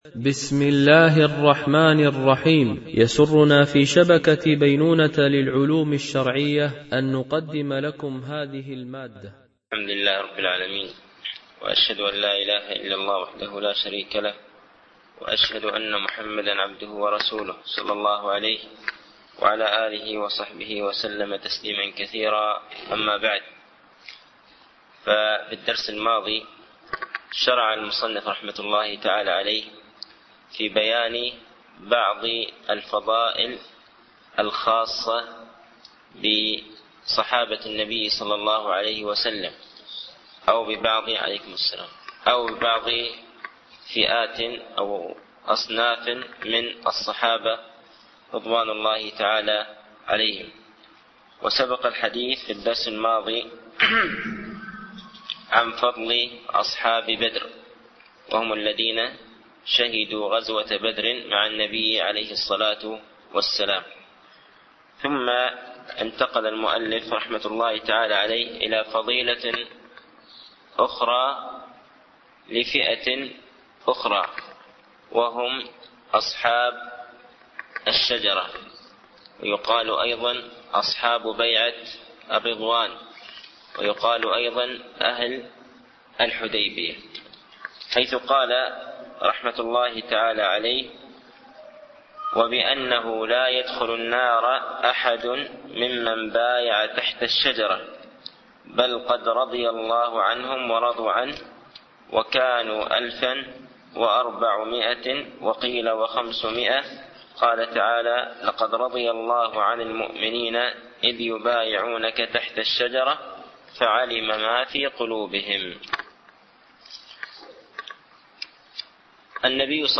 شرح أعلام السنة المنشورة ـ الدرس 139 ( تكملة سؤال - ما الواجب التزامه في أصحاب رسول الله صلى الله عليه وسلم وأهل بيته ؟ )